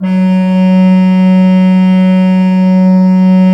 Index of /90_sSampleCDs/Propeller Island - Cathedral Organ/Partition G/HOLZGEDAKT M